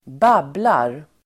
Uttal: [²b'ab:lar]
babblar.mp3